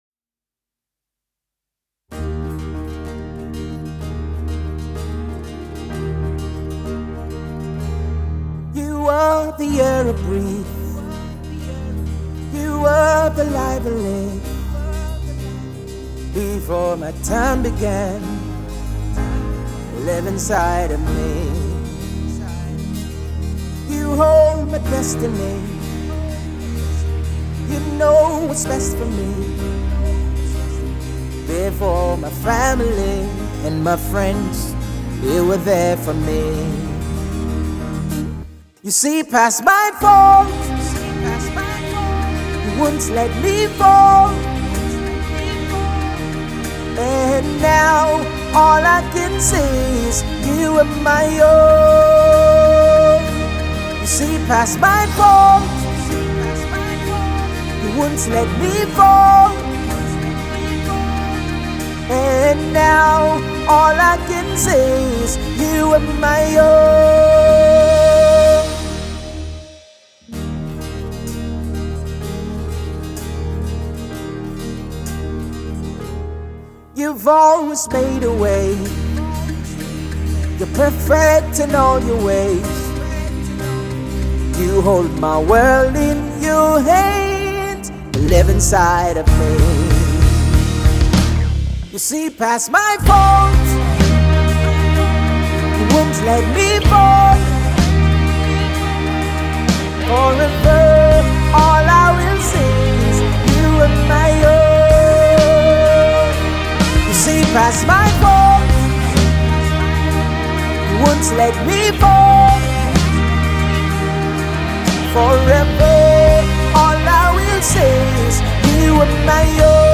Gospel music minister
soft rock blend
expressive vocal delivery